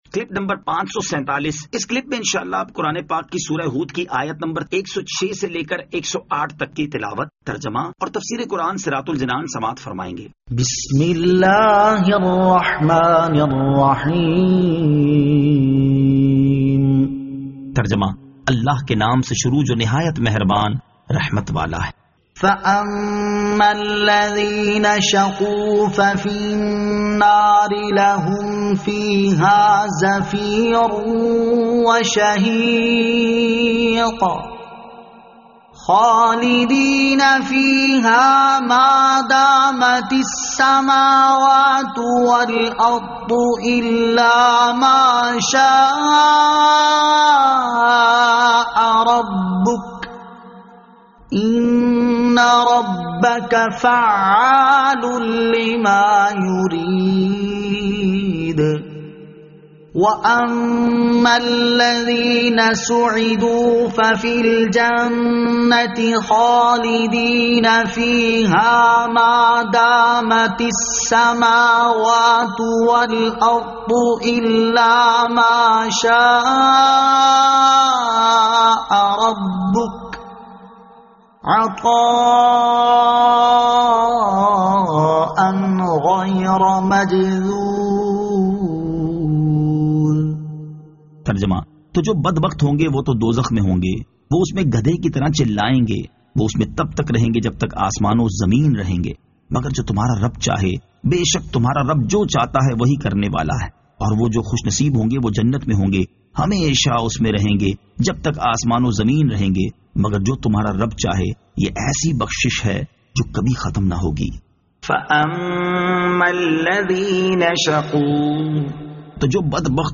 Surah Hud Ayat 106 To 108 Tilawat , Tarjama , Tafseer